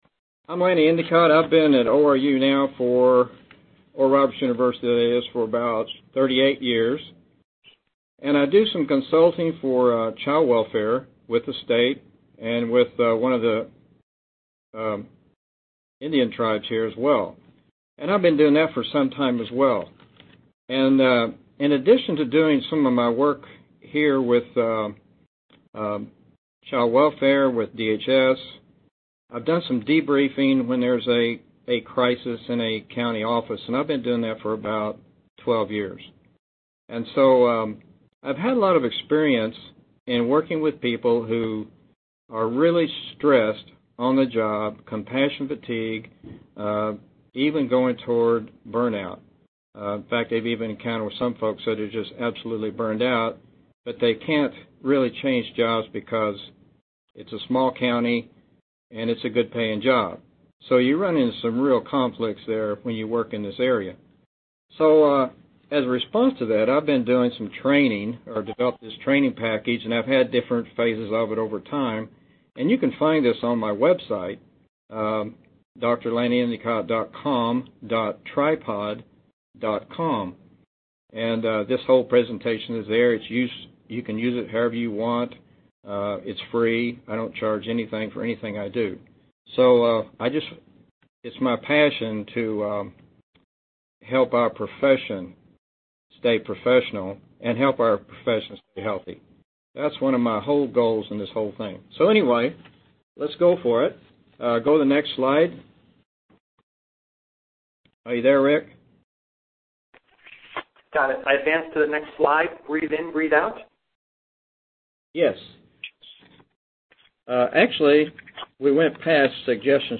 Training format: audio-based